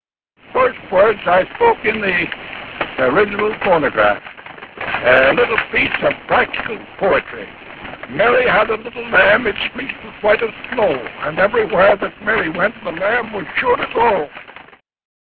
EDISONImage: Thomas Edison in 1888       The Phonograph with the acoustic horn removed Worlds first audio recording machine - 1877 Listen to Edison talking in 1929 about the  first words recorded on his phonograph.